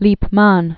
(lēp-män), Gabriel 1845-1921.